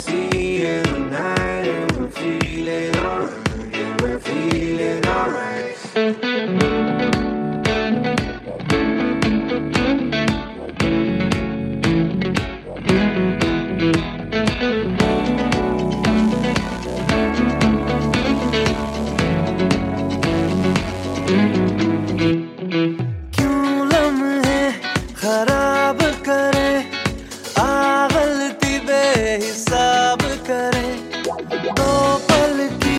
Ringtones Category: Hindi Ringtone Download Mp3 2024